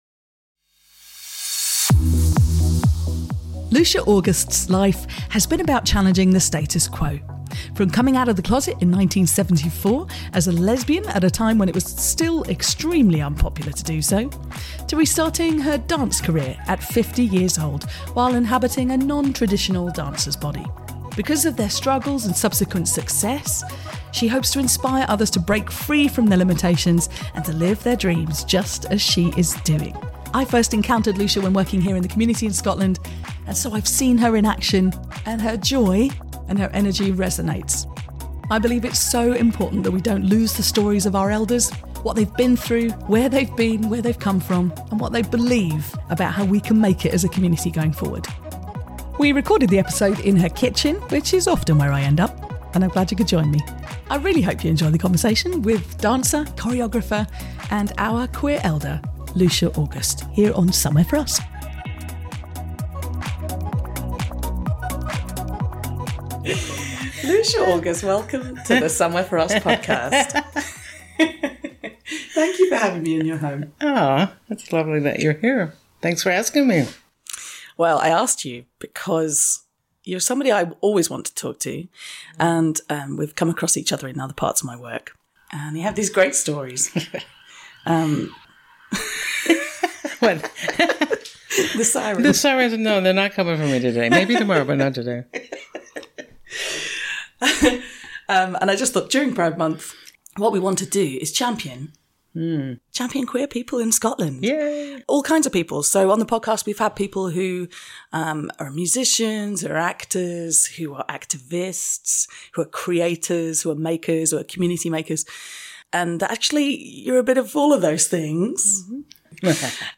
A special conversation championing intergenerational connection and the value of centring queer, elder perspectives with dancer and choreographer